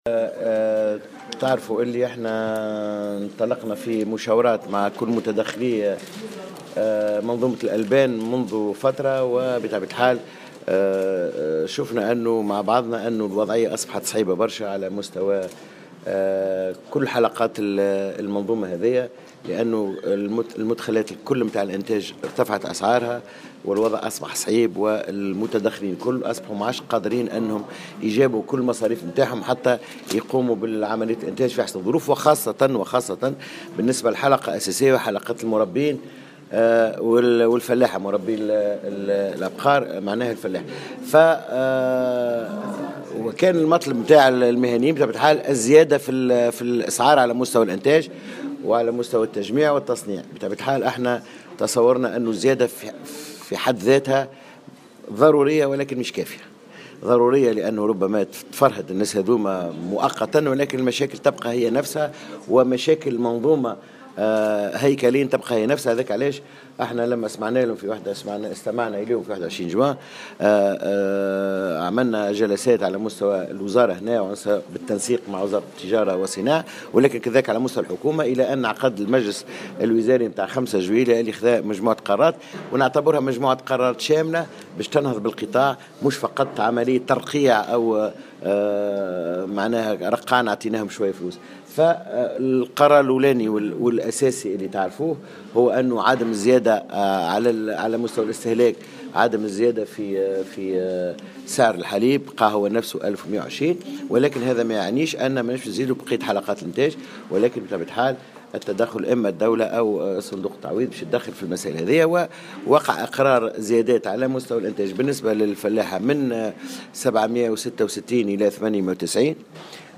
أكد وزير الفلاحة سمير بالطيب في تصريح لمراسل الجوهرة "اف ام" اليوم الإثنين أن المشاورات بخصوص أسعار الحليب بدأت مع كل متدخلي منظومة الألبان وخاصة بعد أن أصبحت وضعية المنتجين والمربين صعبة ومدخلات الإنتاج مرتفعة.